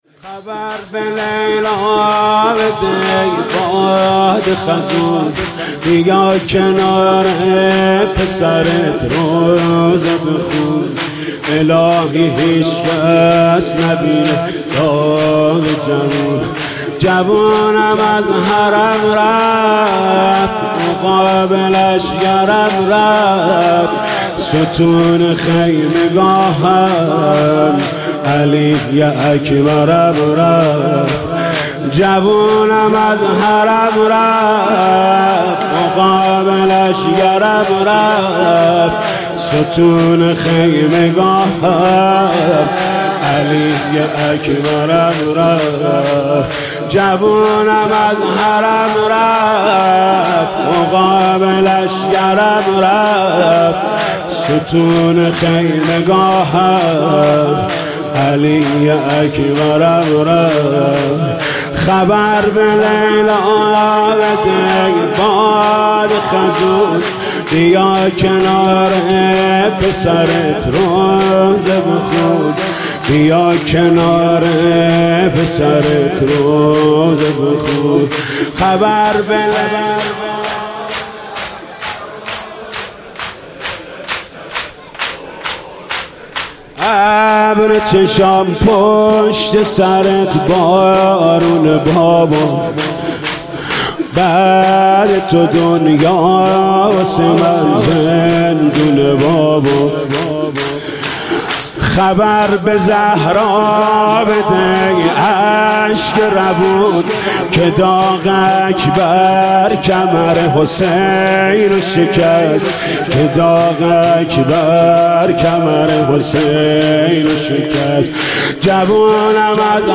دسته بندی :تواشیح , صوت , مدیحه سرایی , مذهبی , مرثیه سرایی
دانلود مداحی و روضه خوانی شب هشتم ماه محرم در سال 1396